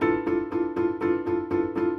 Index of /musicradar/gangster-sting-samples/120bpm Loops
GS_Piano_120-E2.wav